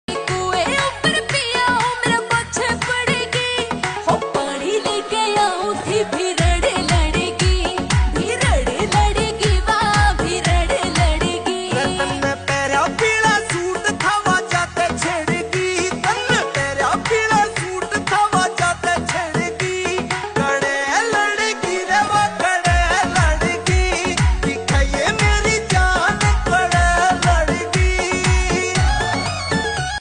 Haryanvi song